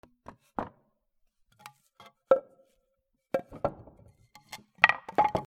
大きめの木をたてかける
『コ カラ』